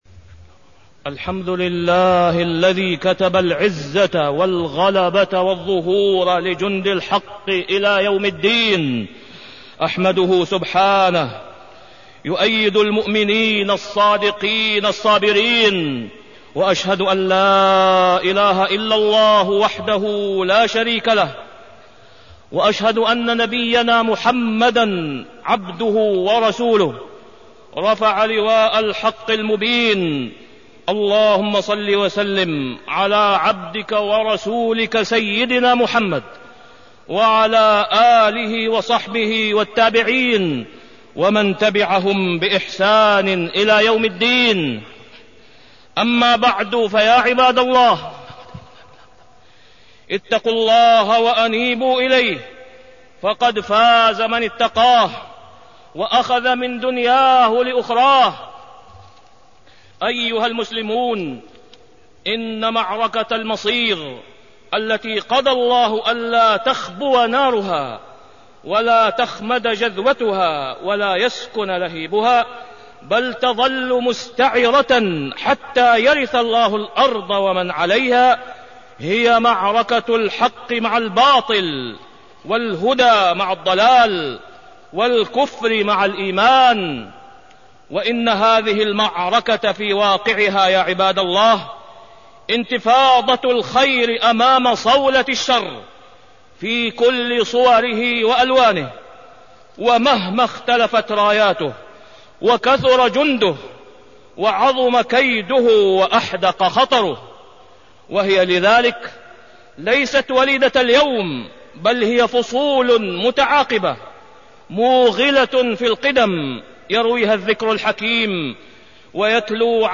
تاريخ النشر ٥ ربيع الأول ١٤٢٣ هـ المكان: المسجد الحرام الشيخ: فضيلة الشيخ د. أسامة بن عبدالله خياط فضيلة الشيخ د. أسامة بن عبدالله خياط معركة الحق والباطل